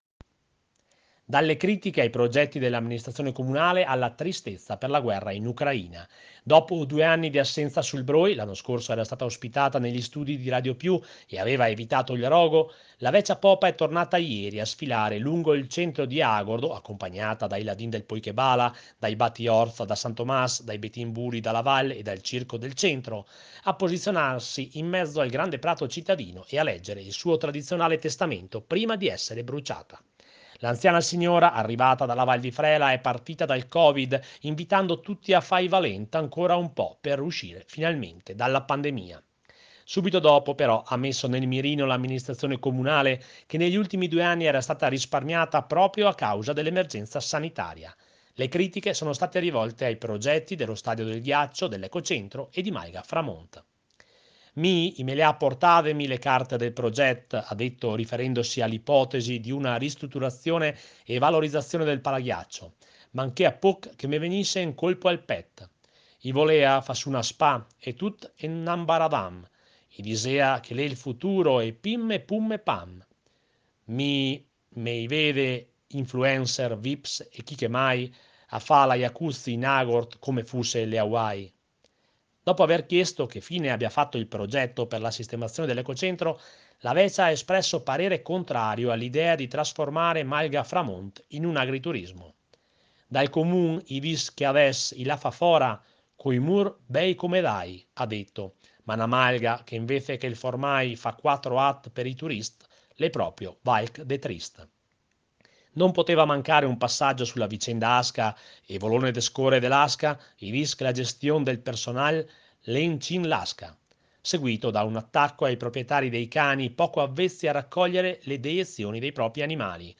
AGORDO La Vecia Popa è tornata sul Broi di Agordo dopo 2 anni di assenza. La sfilata, la festa e il testamento seguito in diretta da Radio Più